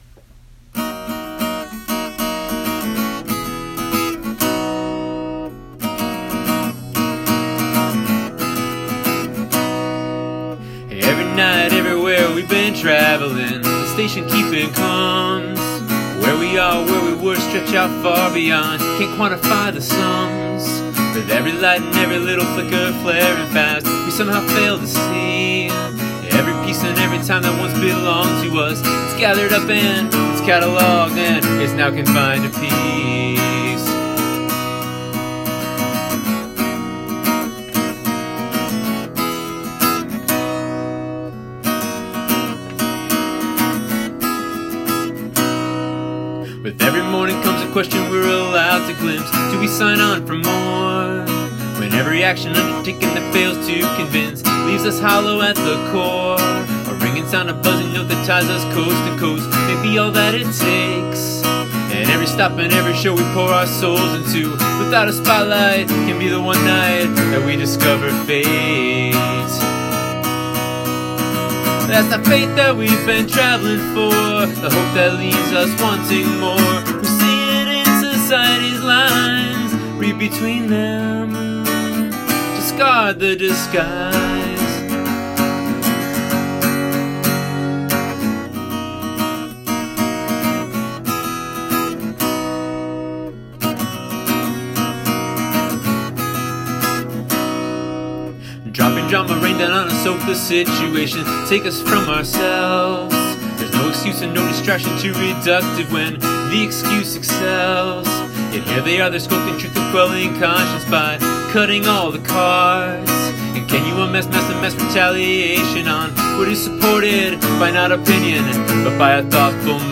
[Acoustic]